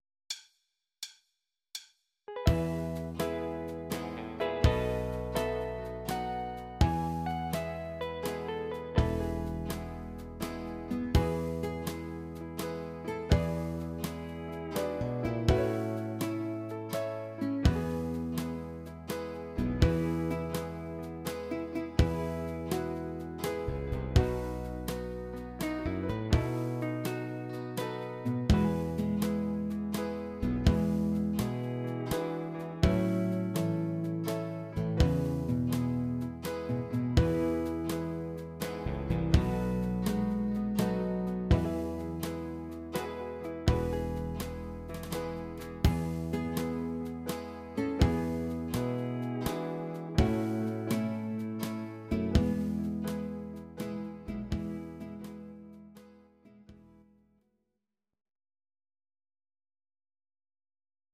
Audio Recordings based on Midi-files
Pop, Jazz/Big Band, Medleys